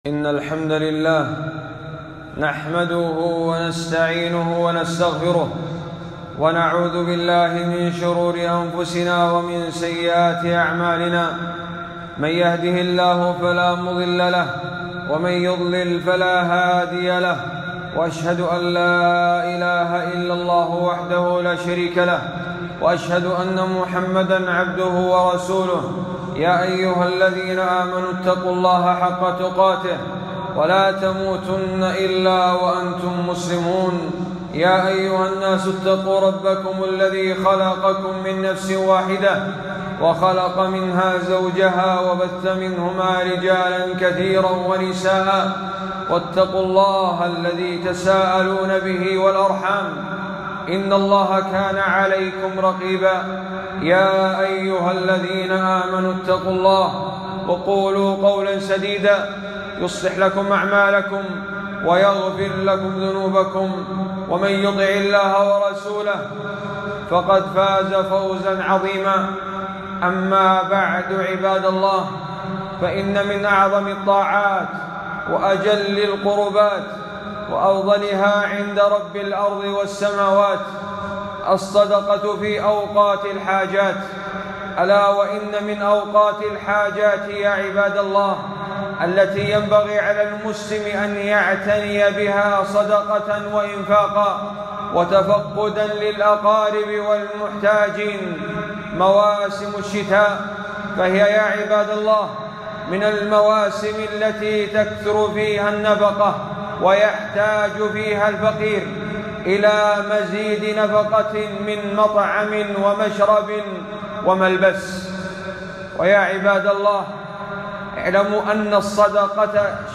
خطبة - الحث على الصدقات وقت الحاجات ونماذج للصحابة